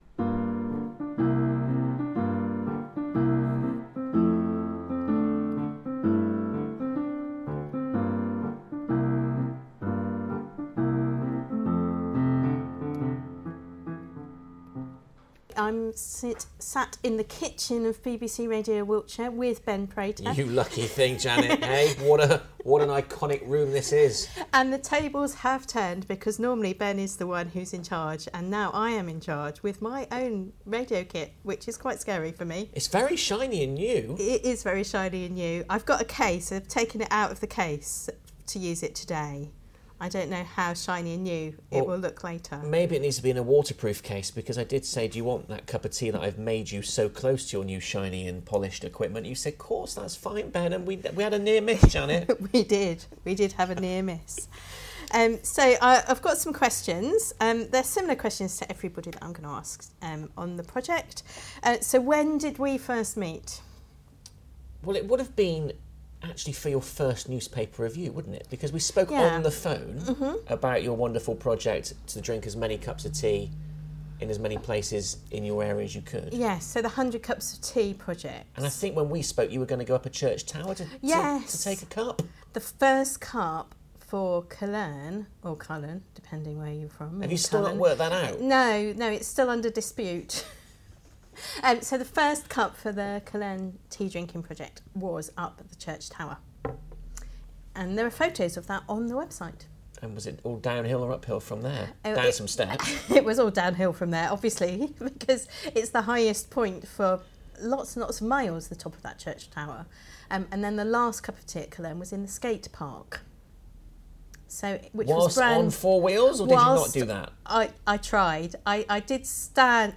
in the kitchen of BBC Wiltshire, recorded after a long breakfast show